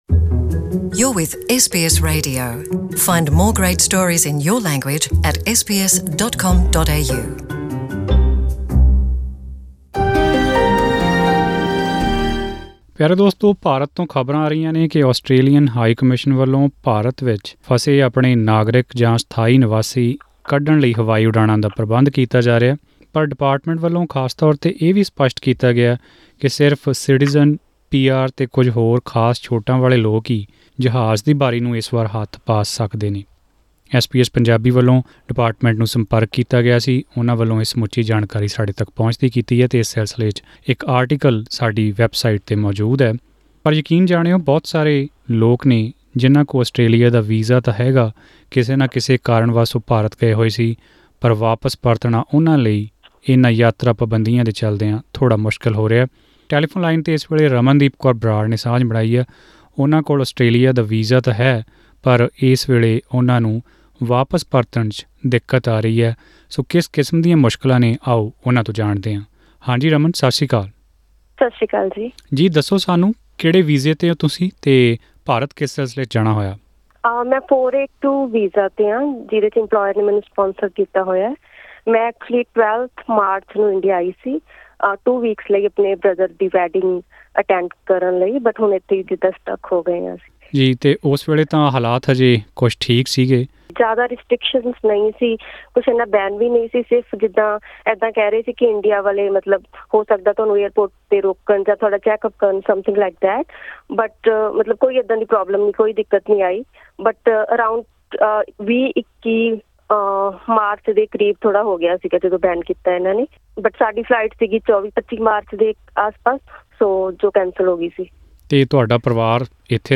ਕਰੋਨਾਵਾਇਰਸ ਕਰਕੇ ਲੱਗੀਆਂ ਯਾਤਰਾ ਪਾਬੰਧੀਆਂ ਪਿੱਛੋਂ ਬਹੁਤ ਸਾਰੇ ਹੁਨਰ-ਅਧਾਰਿਤ ਵੀਜ਼ਾ ਧਾਰਕ ਅਤੇ ਅੰਤਰਰਾਸ਼ਟਰੀ ਵਿਦਿਆਰਥੀ ਜੋ ਇਸ ਸਮੇਂ ਭਾਰਤ ਵਿੱਚ ਆਪਣੇ ਆਪ ਨੂੰ ਫਸੇ ਹੋਏ ਮਹਿਸੂਸ ਕਰ ਰਹੇ ਹਨ, ਆਸਟ੍ਰੇਲੀਆ ਵਾਪਿਸ ਜਾਣ ਲਈ ਬੇਤਾਬ ਹਨ। ਪੂਰੀ ਜਾਣਕਾਰੀ ਲਈ ਸੁਣੋ ਇਹ ਆਡੀਓ ਰਿਪੋਰਟ…
ਐਸ ਬੀ ਐਸ ਪੰਜਾਬੀ ਨਾਲ਼ ਇੰਟਰਵਿਊ ਵਿੱਚ ਤਿੰਨ ਵੀਜ਼ਾ-ਧਾਰਕਾਂ ਨੇ ਦੱਸਿਆ ਕਿ ਉਹਨਾਂ ਦੀ ਆਮਦਨ ਵਿਚਲੀ ਖੜੋਤ, ਵਧੀਆਂ ਆਰਥਿਕ ਲੋੜ੍ਹਾਂ ਜਿਸ ਵਿੱਚ ਹਰ ਮਹੀਨੇ ਜਾਂਦੀਆਂ ਨਿਰੰਤਰ ਕਿਸ਼ਤਾਂ ਅਤੇ ਘਰਾਂ ਦੇ ਕਿਰਾਏ ਸ਼ਾਮਿਲ ਹਨ, ਉਨ੍ਹਾਂ ਲਈ ਕਾਫੀ ਮੁਸ਼ਕਿਲ ਪੈਦਾ ਕਰ ਰਹੇ ਹਨ।